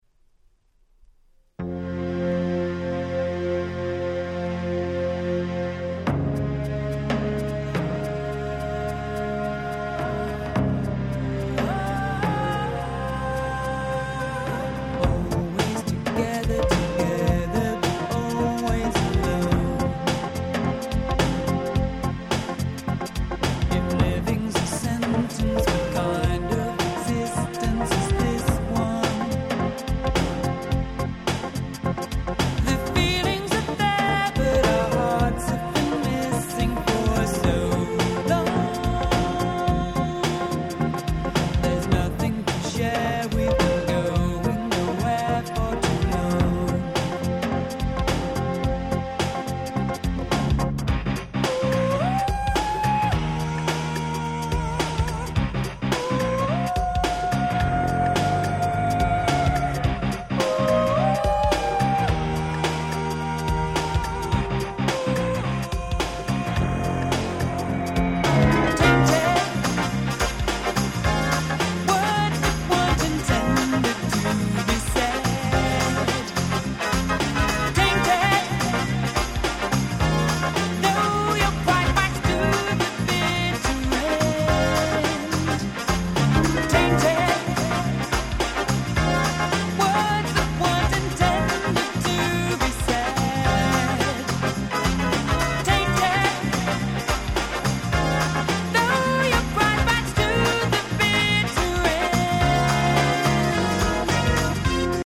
89' Nice UK R&B LP !!